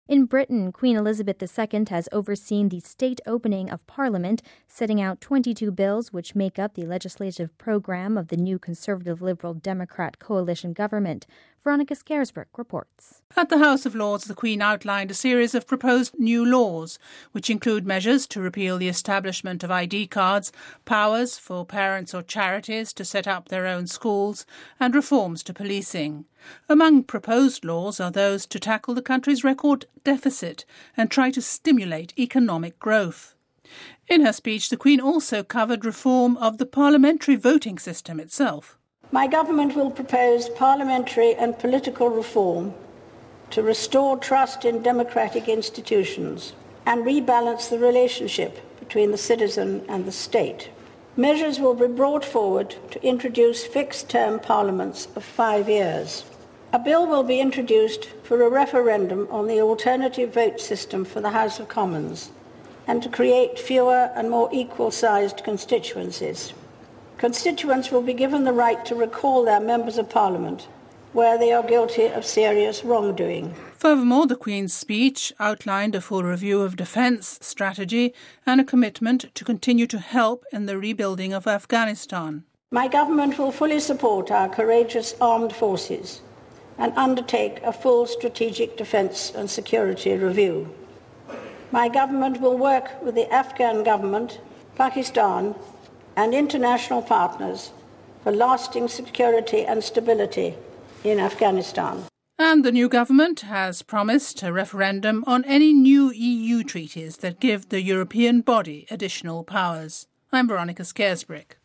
(25 May 10 - RV) In Britain, Queen Elizabeth II has overseen the state opening of parliament, setting out 22 bills which make up the legislative programme of the new Conservative-Liberal Democrat coalition government. We have this report: